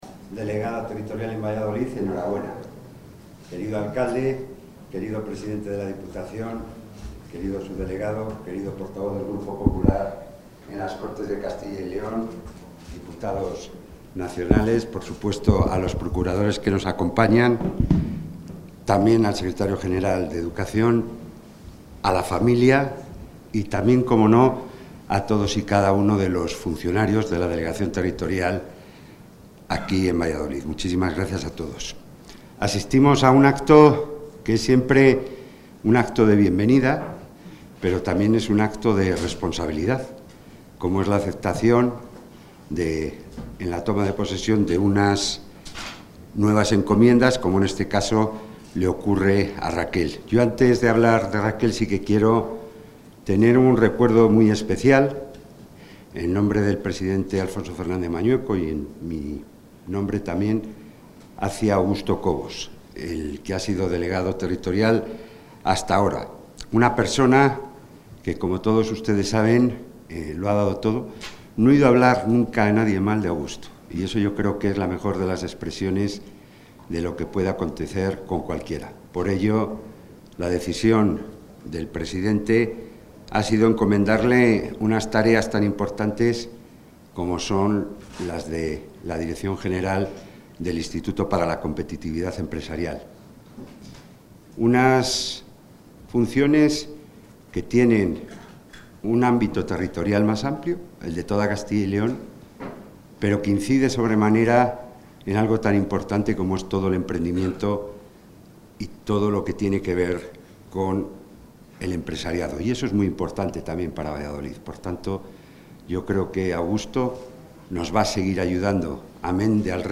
Intervención del consejero de la Presidencia.
El consejero de la Presidencia, Jesús Julio Carnero, ha presidido hoy la toma de posesión de la delegada territorial de la Junta en Valladolid, Raquel Lourdes Alonso Hernández.